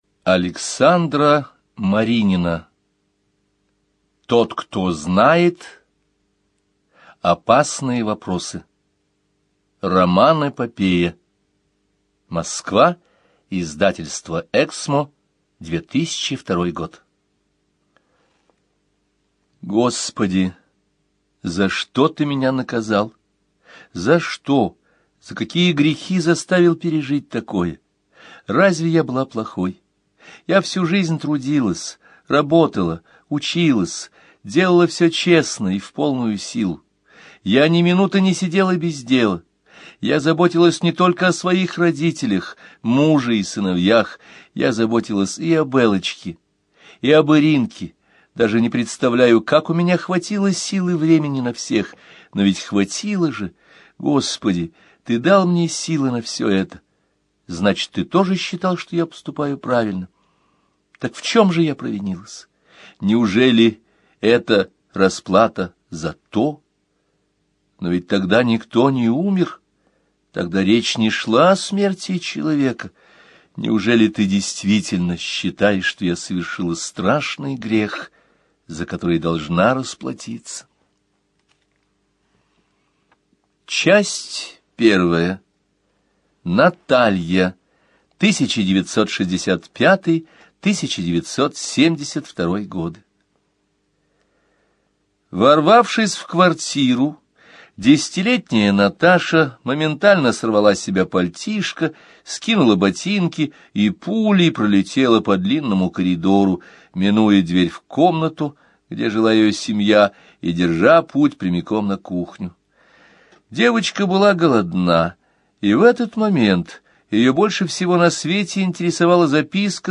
Аудиокнига Тот, кто знает. Опасные вопросы | Библиотека аудиокниг